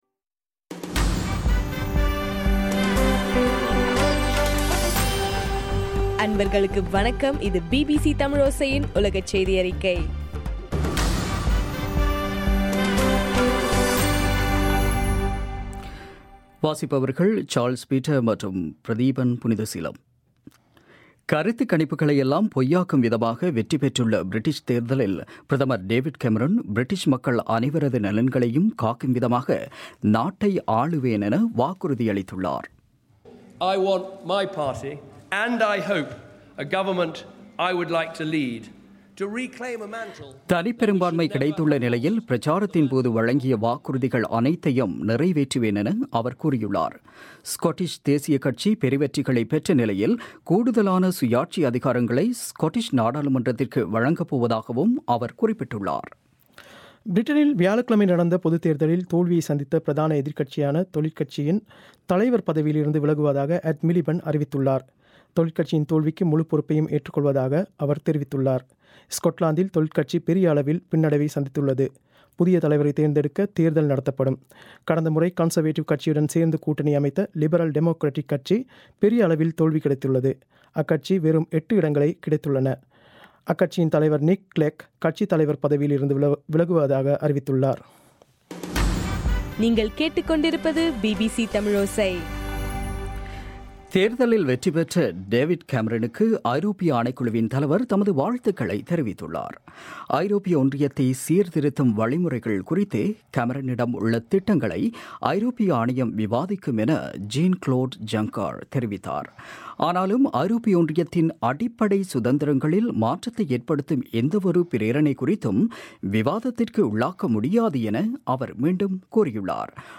இன்றைய ( மே 8) பிபிசி தமிழோசை உலக செய்தியறிக்கை